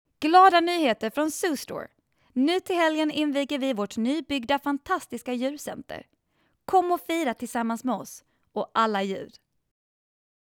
Kommerziell, Natürlich, Verspielt, Warm, Corporate
Erklärvideo